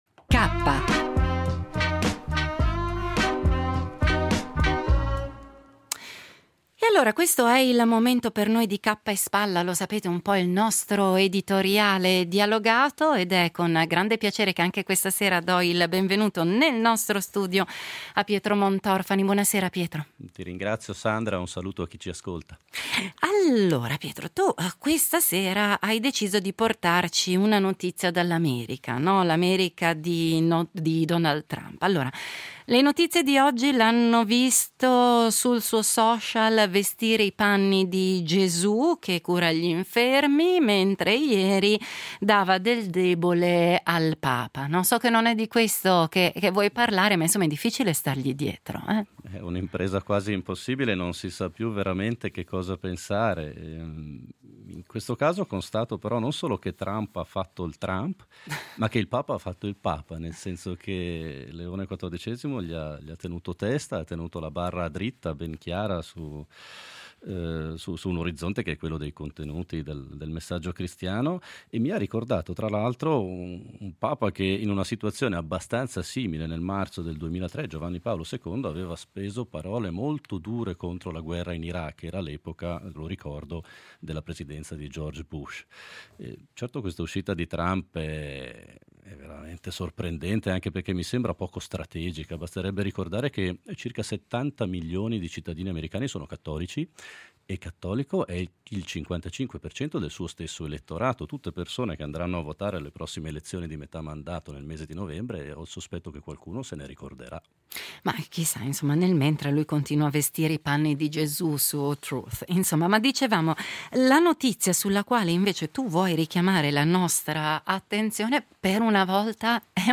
L’editoriale del giorno